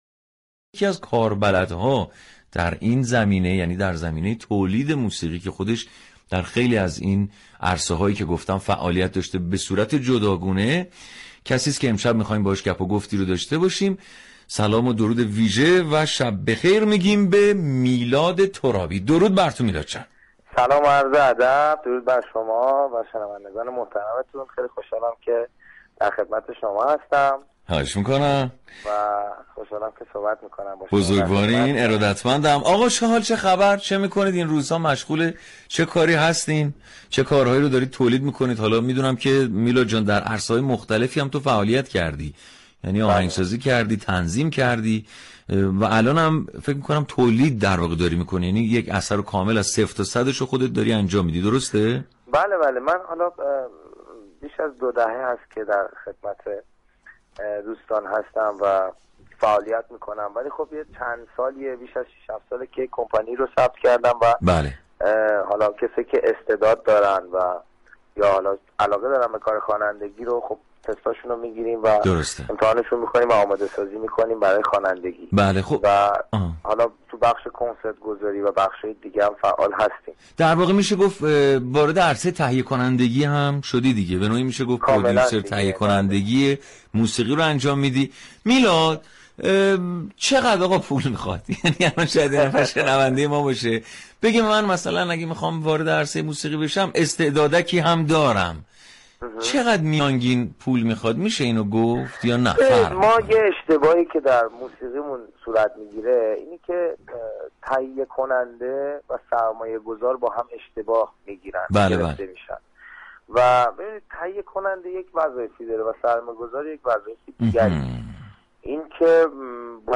به گزارش روابط عمومی رادیو صبا برنامه صباهنگ روز دوشنبه ششم میزبان میلاد ترابی خواننده و تنظیم كننده خوب كشورمان بود و باوی درباره موسیقی و تهیه كنندگی در این عرصه گفتگو كرد.